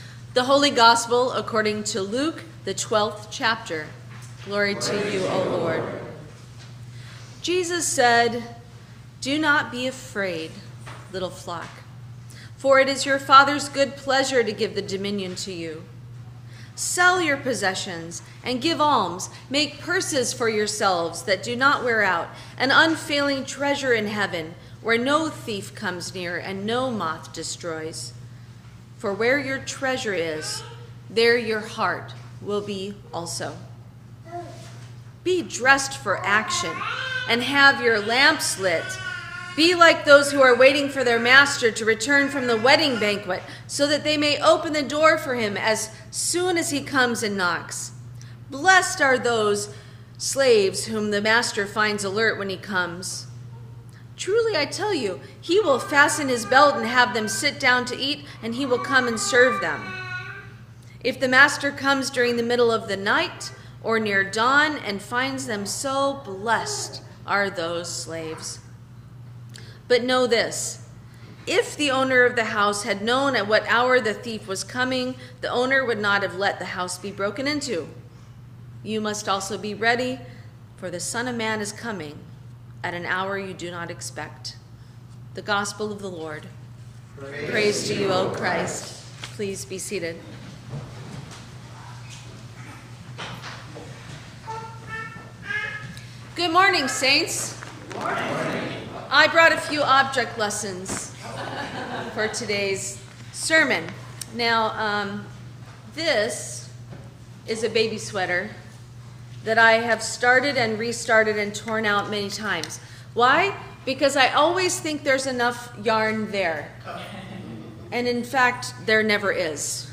Sermon for the Ninth Sunday after Pentecost 2025